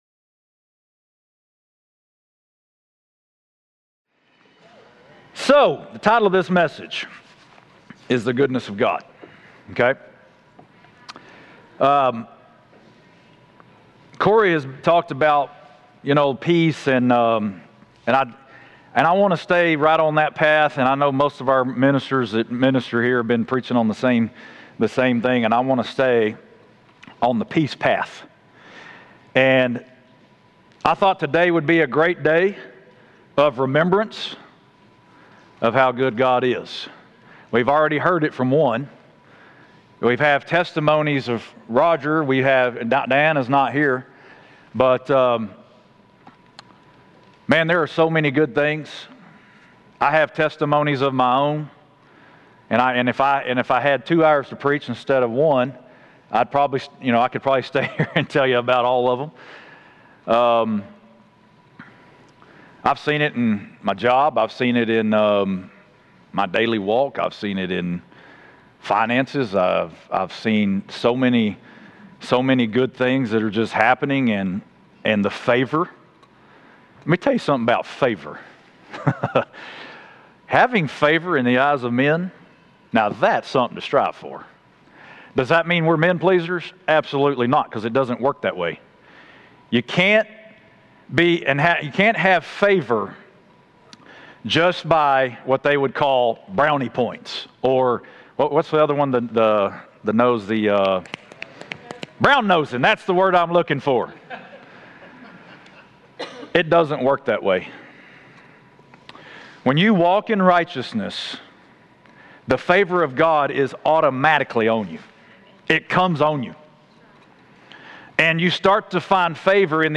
Live Stream Our Complete Service Sunday mornings at 10:30am Subscribe to our YouTube Channel to watch live stream or past Sermons Listen to audio versions of Sunday Sermons